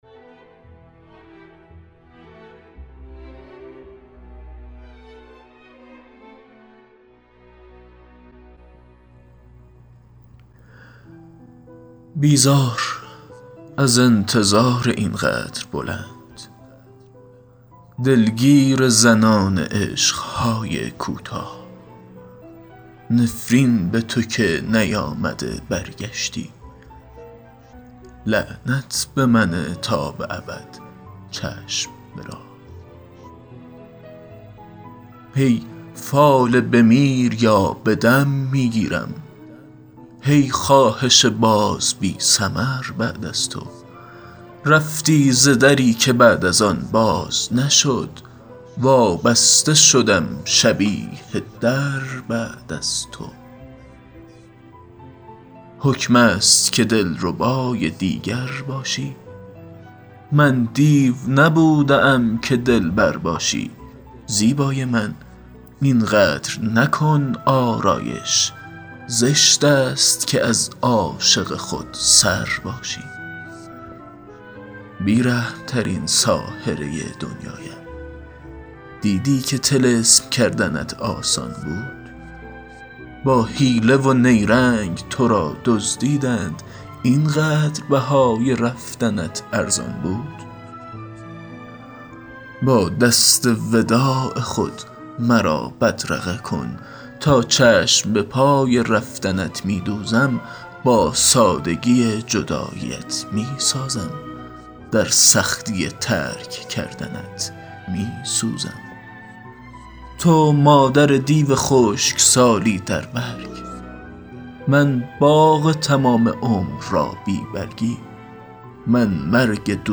دکلمه شعر